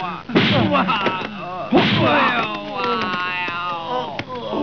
That, and the fact that every defeated opponent makes the same sound, a sort of pained "
Ai-ow-oooowah".
ow.wav